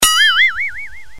دانلود آهنگ پیامک 11 از افکت صوتی اشیاء
دانلود صدای پیامک 11 از ساعد نیوز با لینک مستقیم و کیفیت بالا
جلوه های صوتی